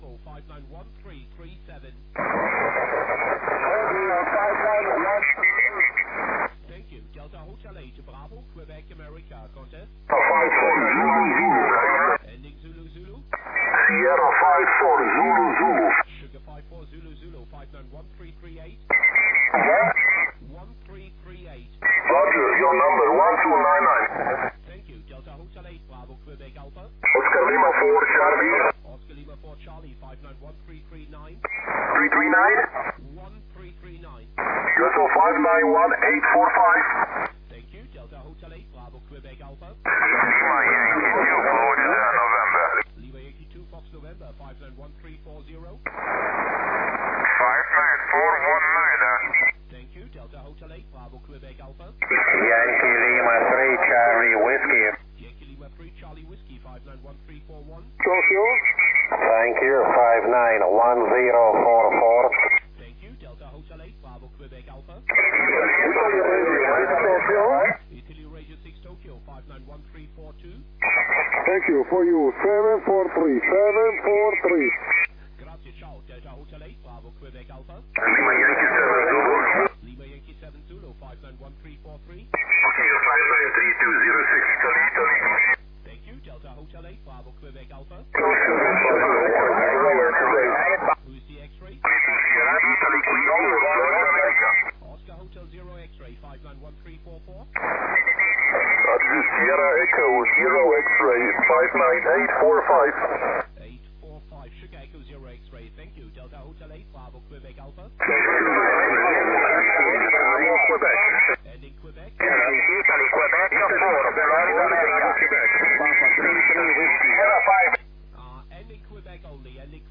A few minutes of the 80 m pileup:
80m-snippet.mp3